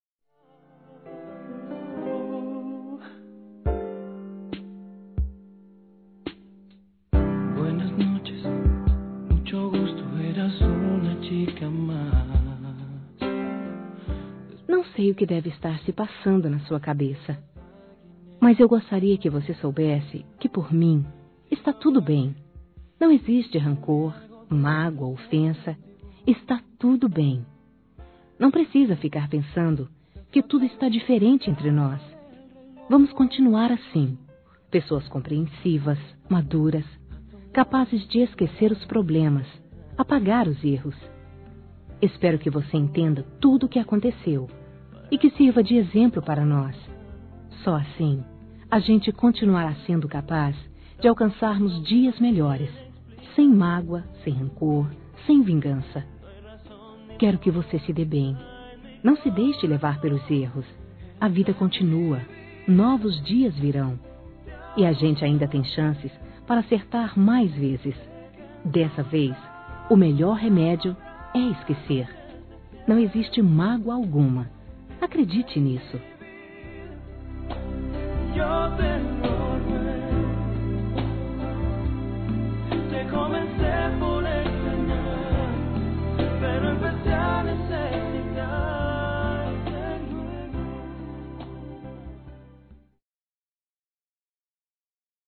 Não Existem Mágoas | Voz Feminina – Flores com Carinho
Mensagens Fonadas